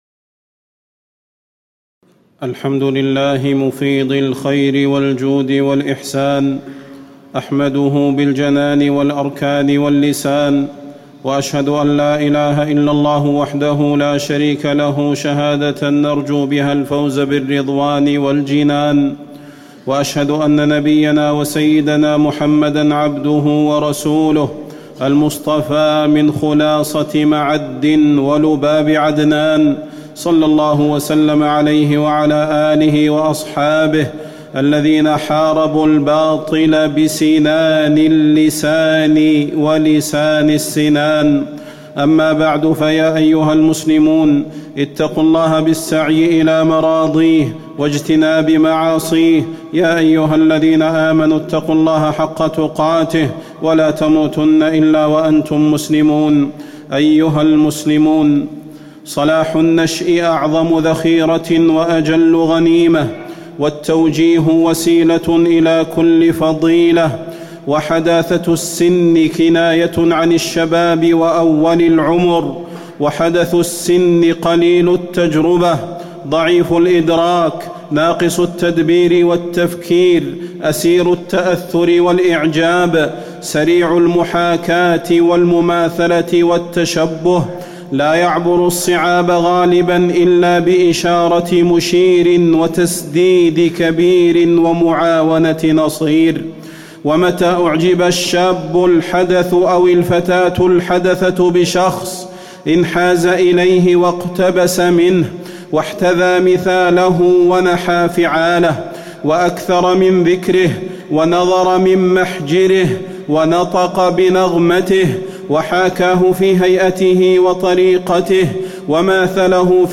تاريخ النشر ١ ربيع الثاني ١٤٣٨ هـ المكان: المسجد النبوي الشيخ: فضيلة الشيخ د. صلاح بن محمد البدير فضيلة الشيخ د. صلاح بن محمد البدير تربية الأولاد بين المراقبة والإهمال